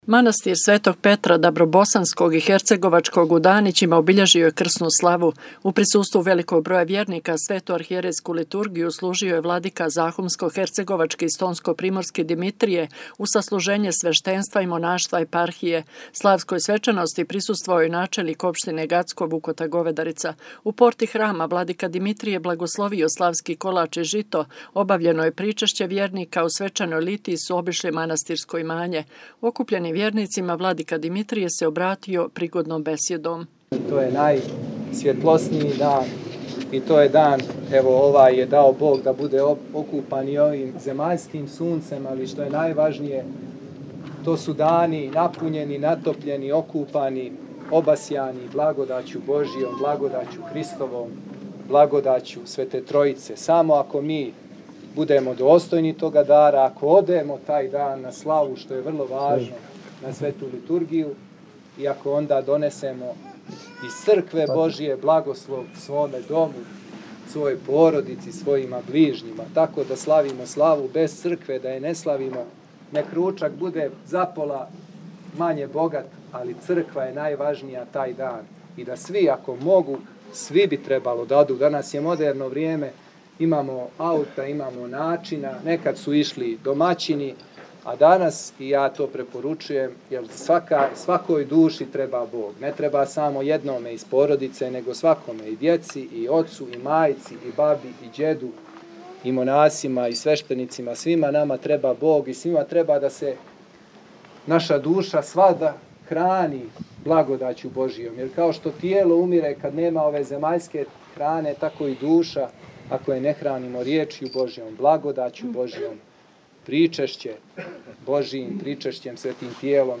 Manastir Sv. Petra Dabrobosanskog u Danićima obilježio je Krsnu slavu.
Okupljenim vjernicima vladika Dimitrije se obratio prigodnom besjedom rekavši: